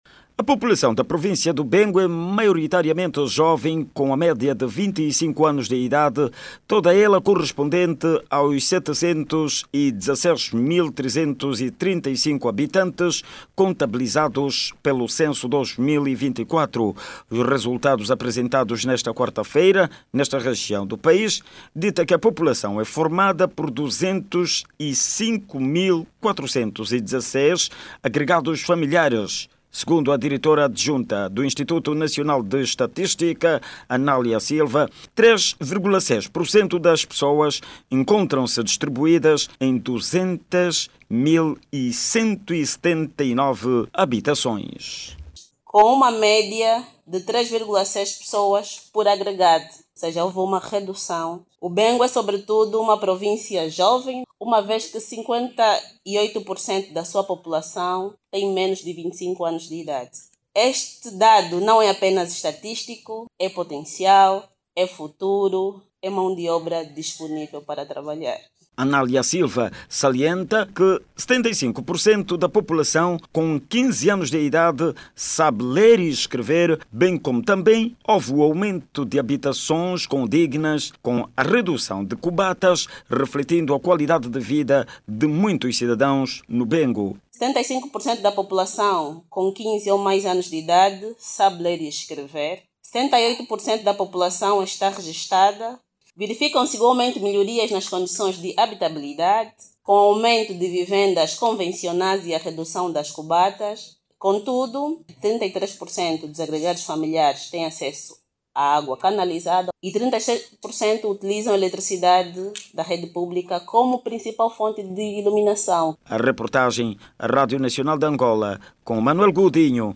O dado do censo populacional 2024, segundo as autoridades, reflecte força útil de trabalha. Os dados dão igualmente conta de que 75 porcento da população sabe ler e escrever e 33 porcento da população tem acesso à rede pública de água. Ouça o desenvolvimento desta matéria na voz do jornalista